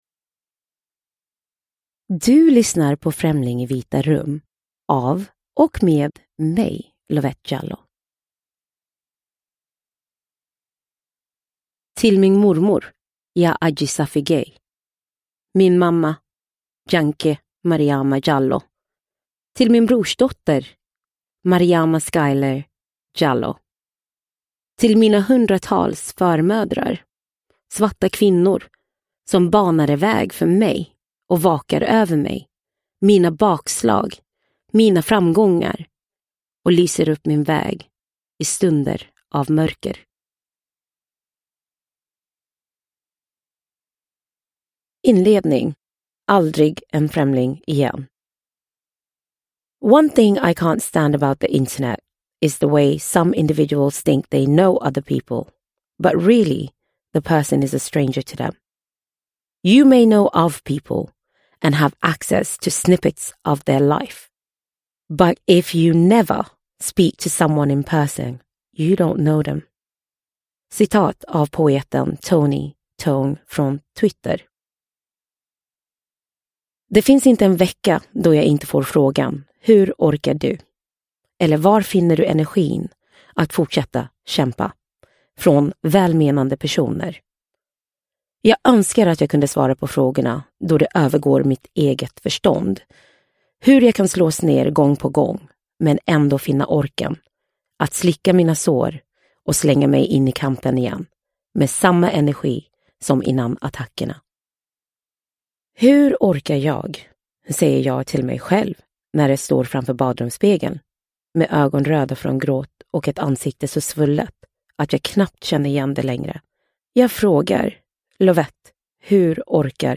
Främling i vita rum – Ljudbok – Laddas ner